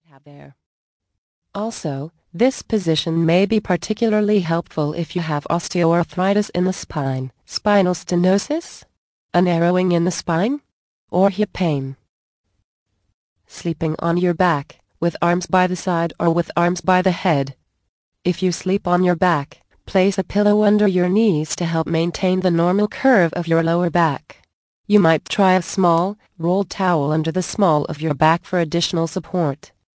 The Magic of Sleep audio book Vol. 6 of 14, 62 min.